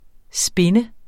spinne 2 verbum Bøjning -r, -de, -t Udtale [ ˈsbenə ] Oprindelse kendt fra 1996 fra engelsk, spin 'dreje, rotere', betydning 2 efter spindoktor Betydninger 1.